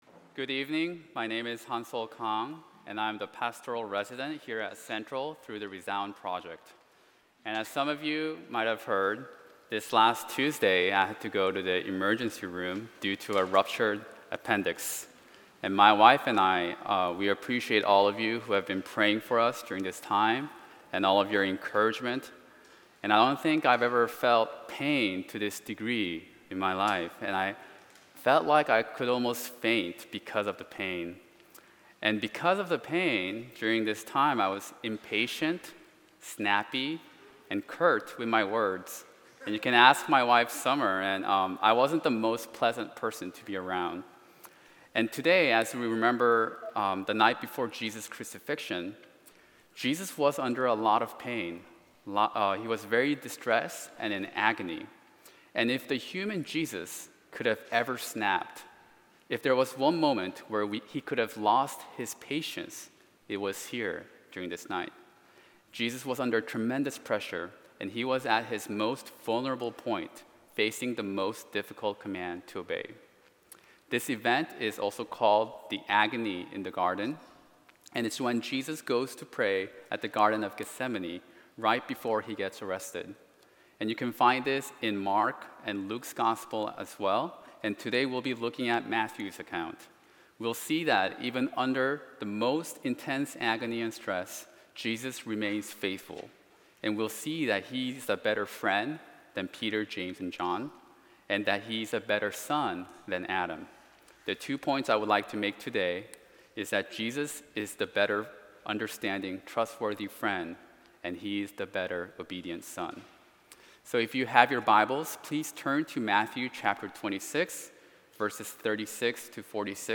Back to Sermons